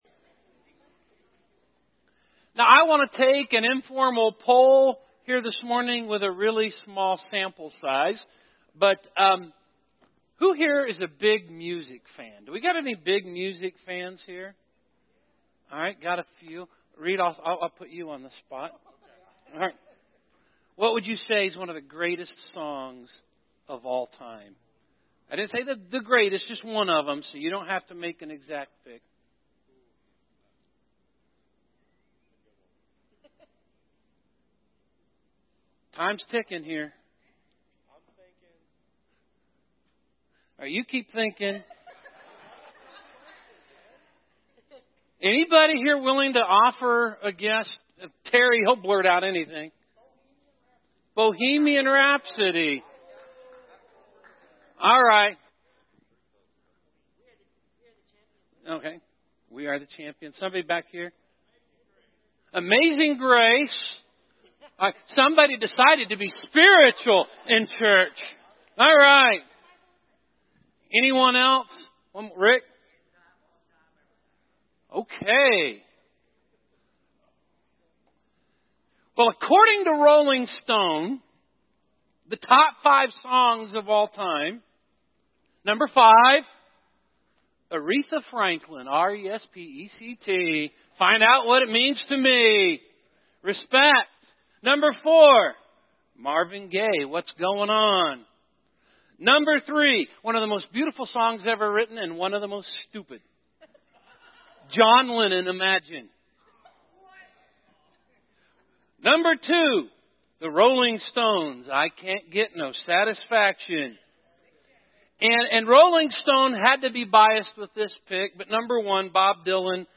Word of God Psalms Core 52 Audio Sermon Save Audio Save PDF Psalm 1 highlights the way of blessing which leads to true happiness.